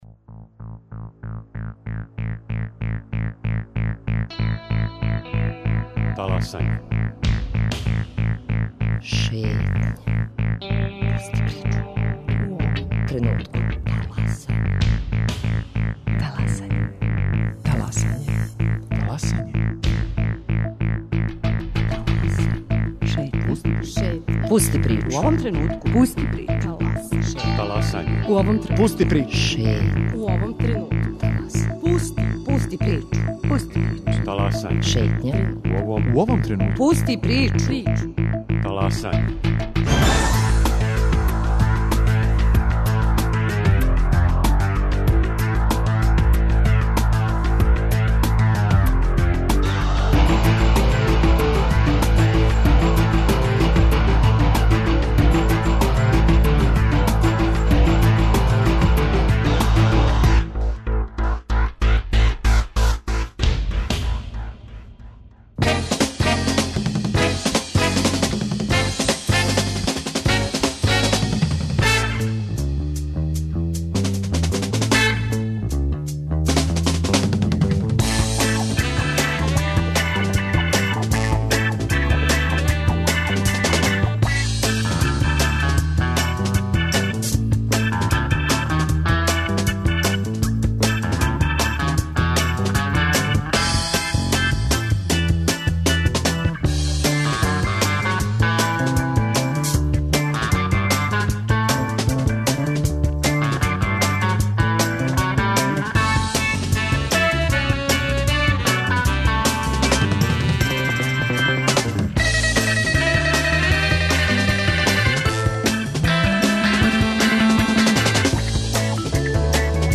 Детаље, ексклузивно за наш програм, објашњава председник тог Комитета - шпански судија Луис Химена Кесада.